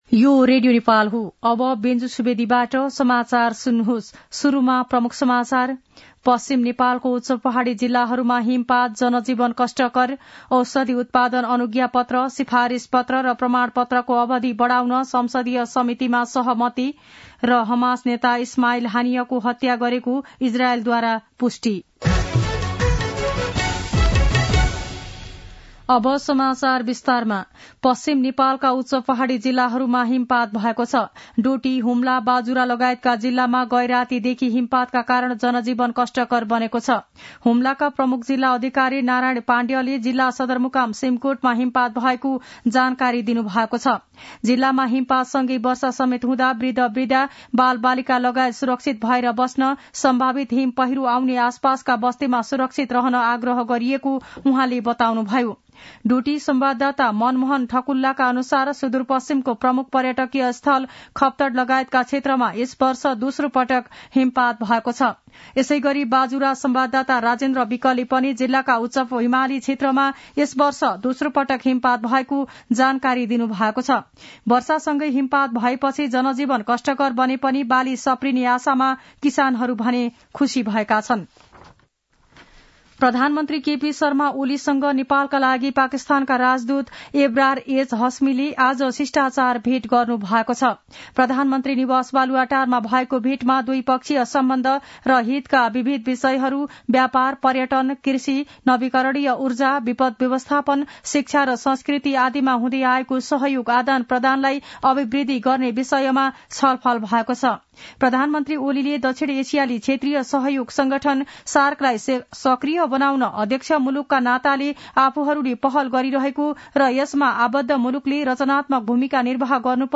दिउँसो ३ बजेको नेपाली समाचार : १० पुष , २०८१
3-pm-nepali-news-1-14.mp3